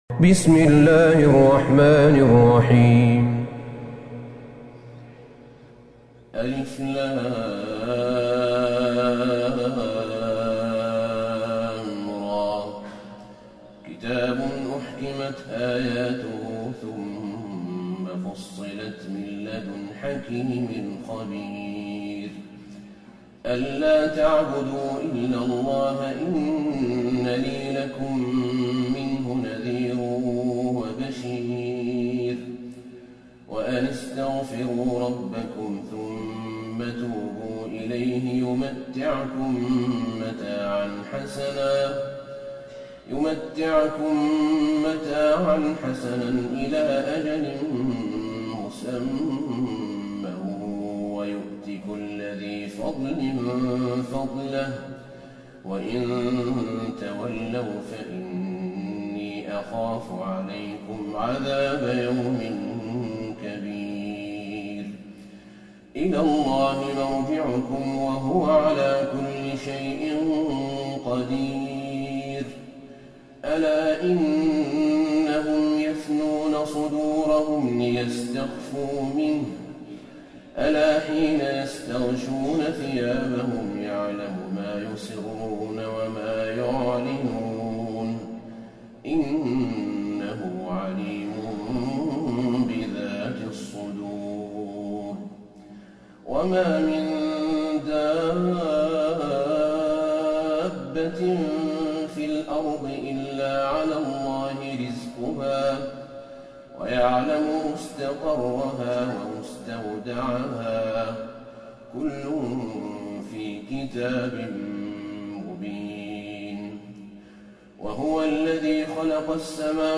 سورة هود Surat Hud > مصحف الشيخ أحمد بن طالب بن حميد من الحرم النبوي > المصحف - تلاوات الحرمين